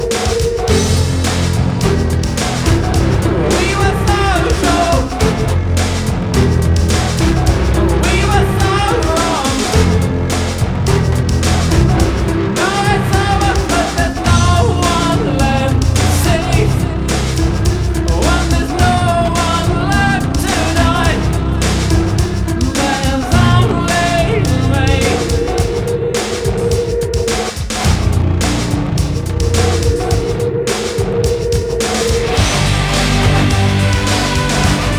Rock Electronic Industrial Alternative Punk New Wave
Жанр: Рок / Альтернатива / Электроника / Классика